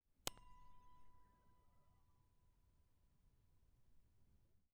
Metal_73.wav